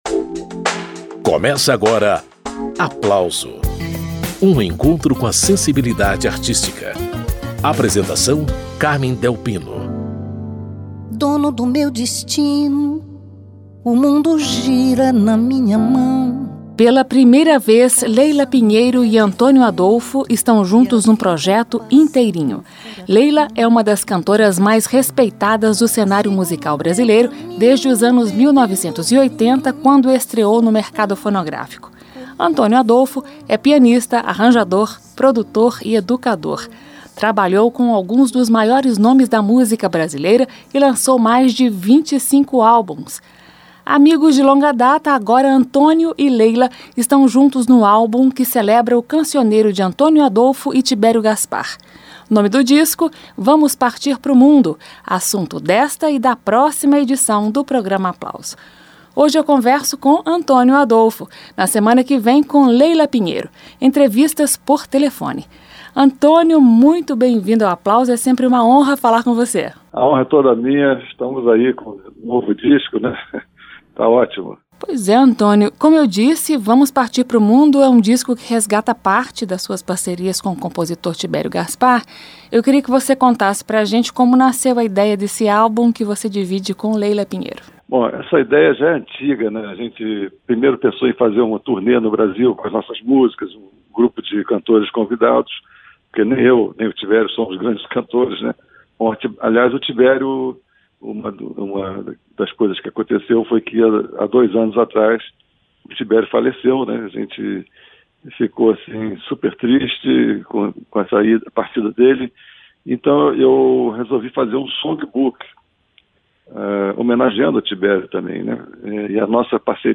Nas duas edições, músicas leves e solares: um respiro nesses tempos difíceis de pandemia e isolamento social.